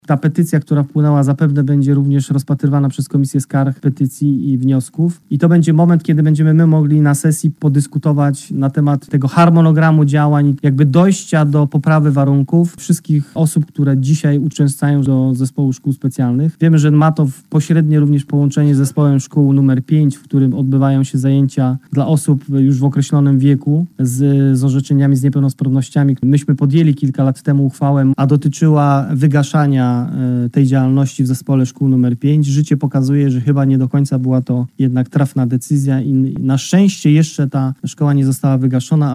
Petycję przedstawił na dzisiejszej sesji radnym przewodniczący Rady Powiatu, Zdzisław Rygliel, dodając…